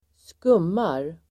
Uttal: [²sk'um:ar]